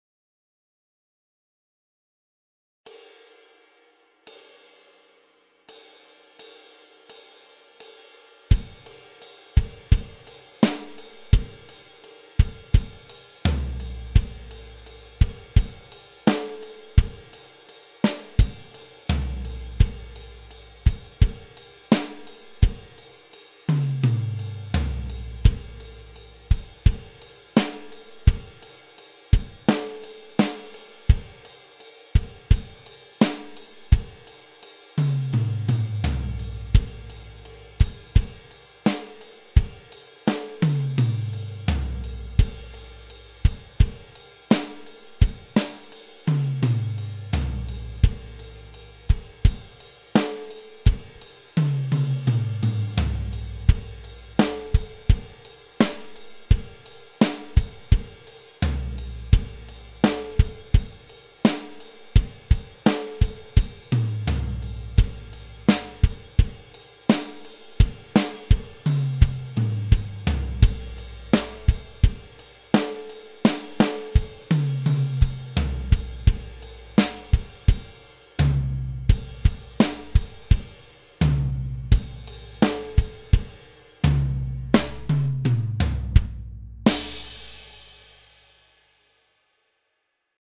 schnell